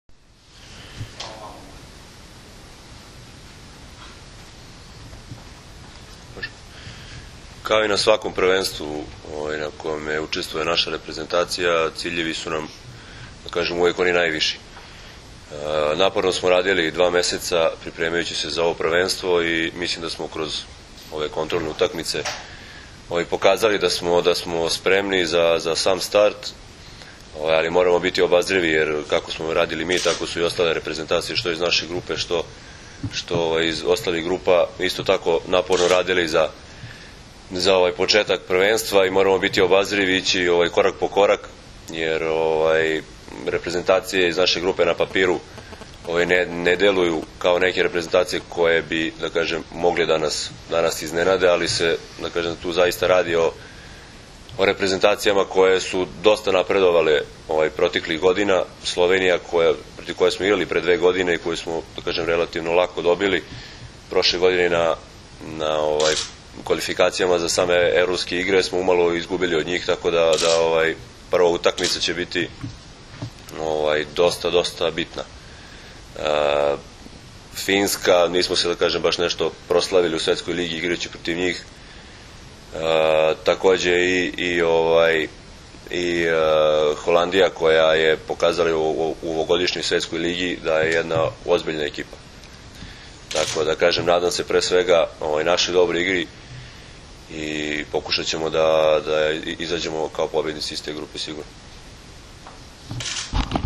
IZJAVA DRAGANA STANKOVIĆA